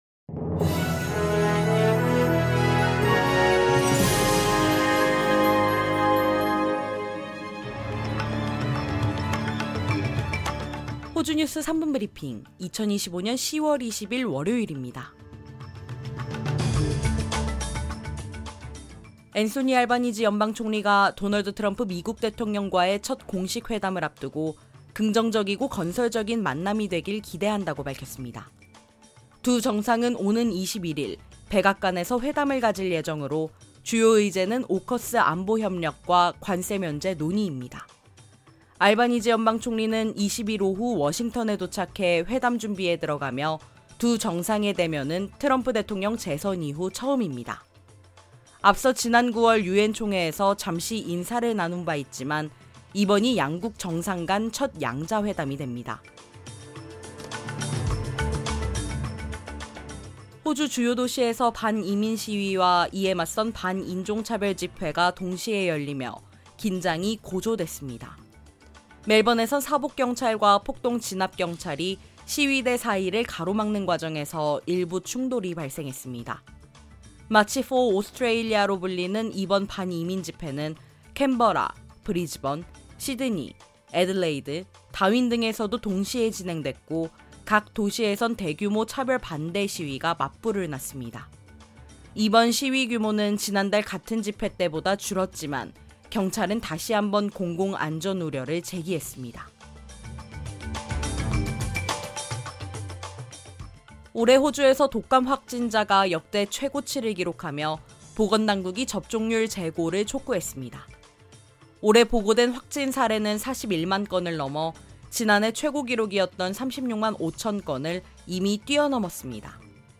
호주 뉴스 3분 브리핑: 2025년 10월 20일 월요일